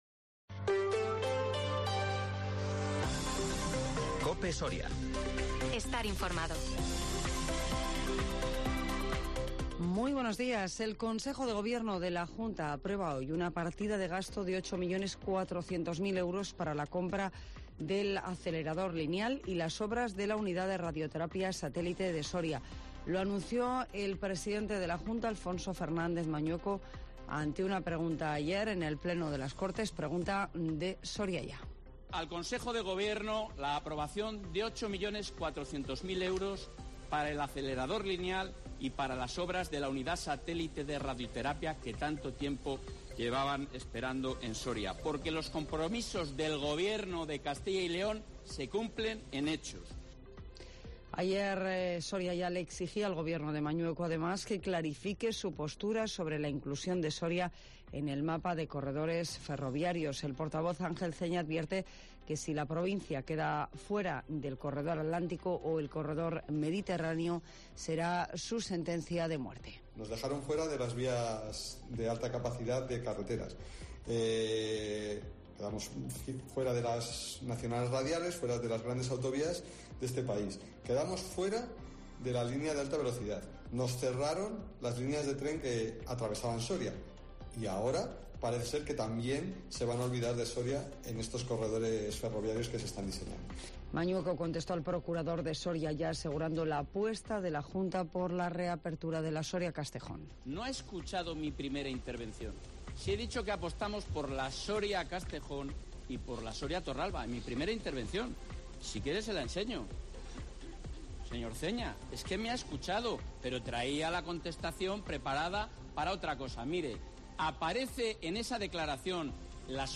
AUDIO: Las noticias en COPE Soria